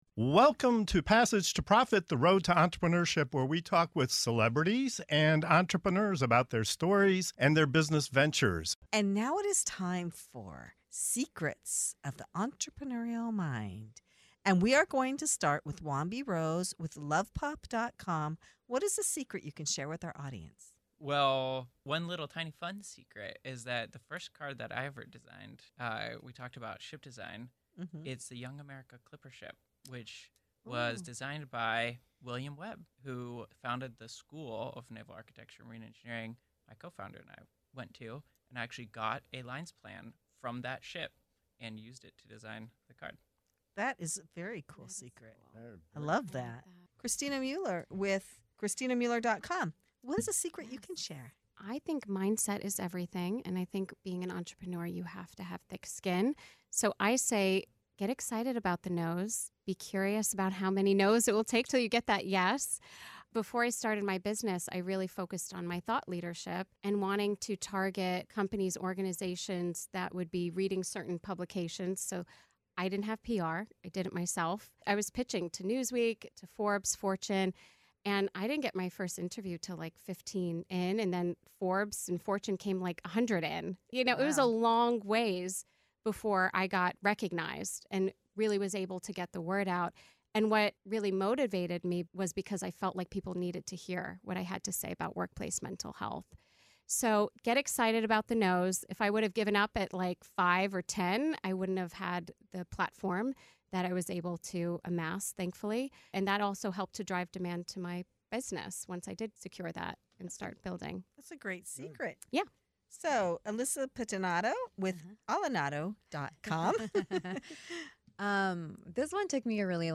From turning 100 media rejections into features in major publications, to escaping “the gap” when setbacks hit, to building a business through radical authenticity, these entrepreneurs reveal the mental frameworks that drive growth. Plus, why today’s founders shouldn’t rely on just one AI tool — and what happens when you test them against each other.